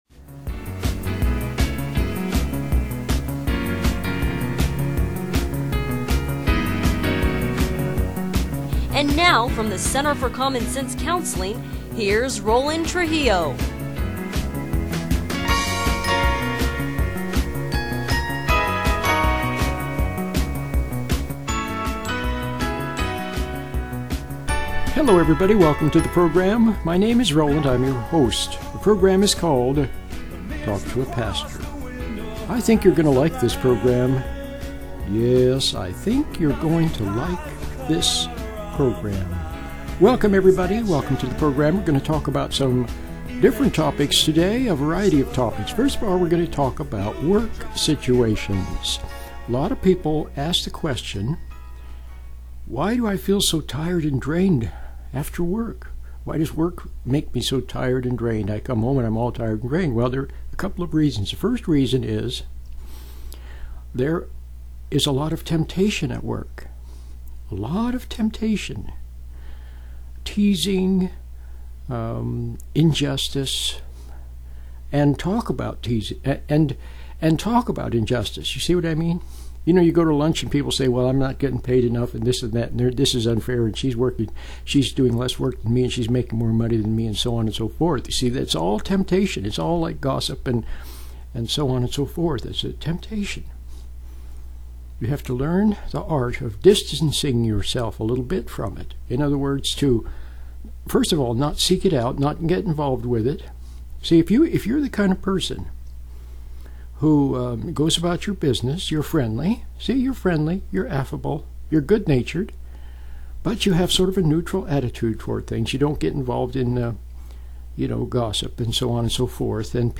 I made a short lecture about patience - you should listen to it . Do you know what Jesus means by patience?